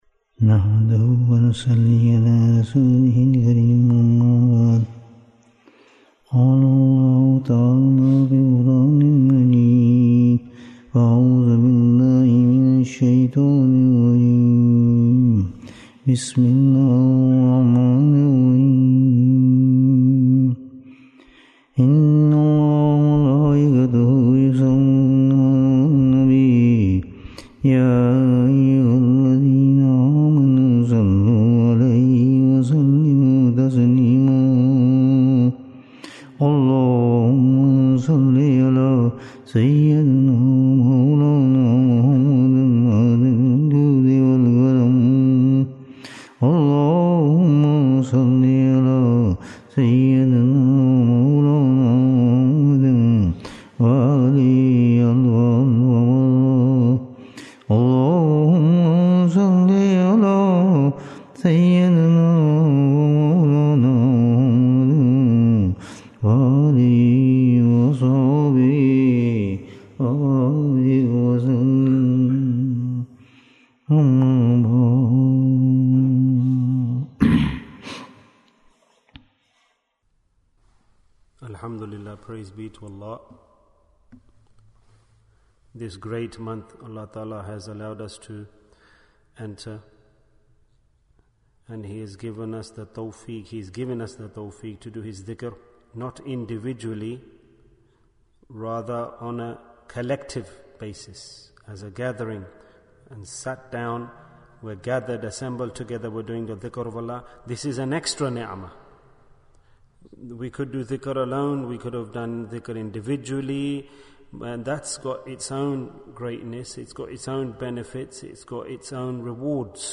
Ramadhan & Quran Bayan, 70 minutes24th March, 2023